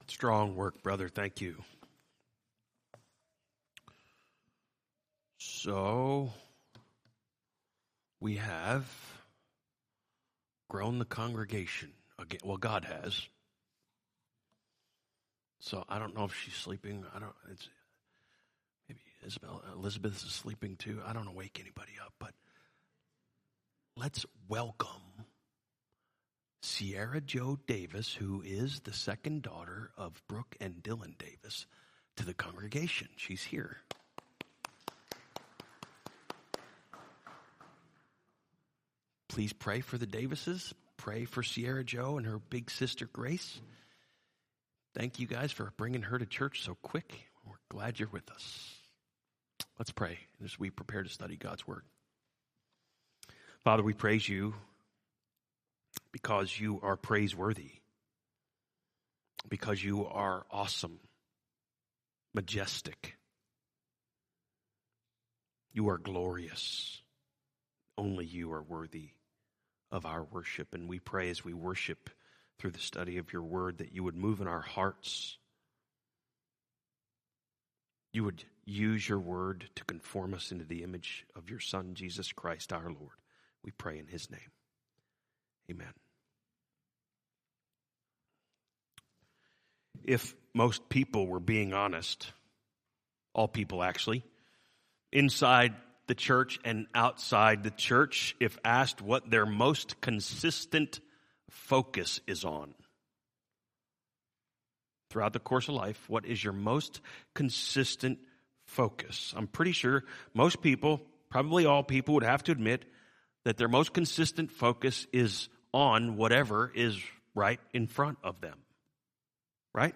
From Series: "2022 Sermons"